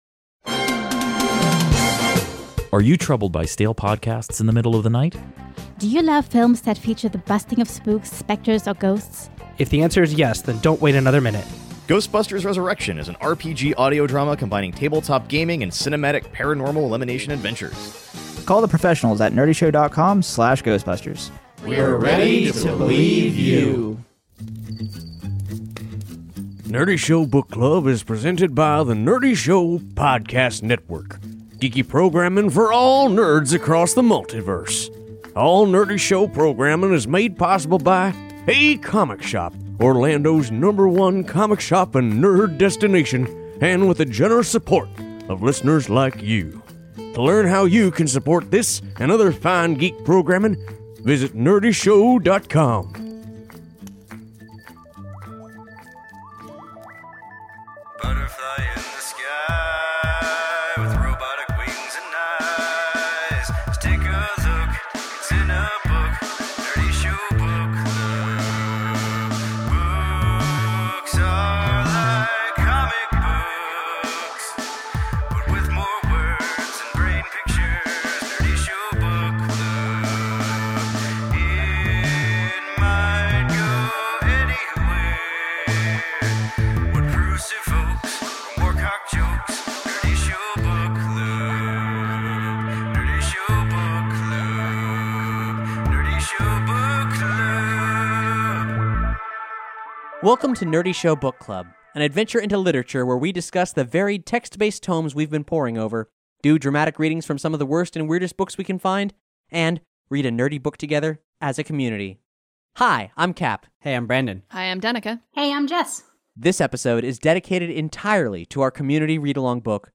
a dramatic reading